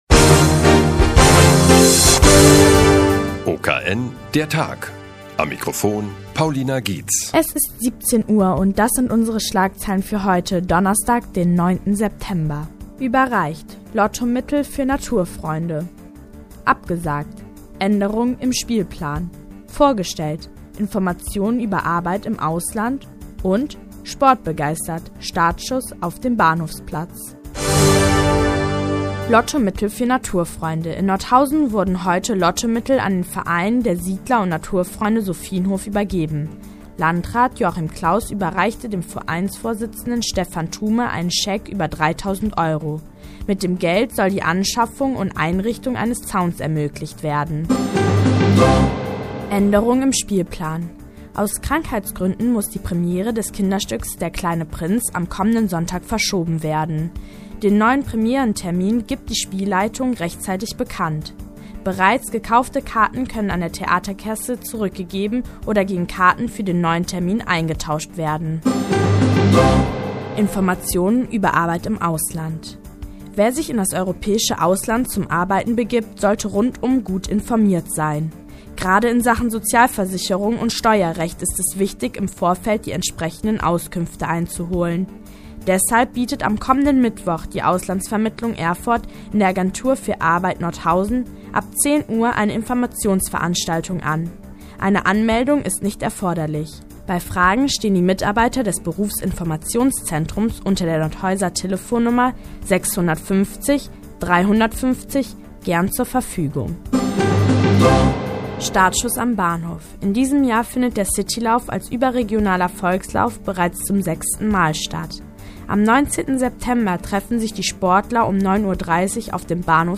Die tägliche Nachrichtensendung des OKN ist auch in der nnz zu hören.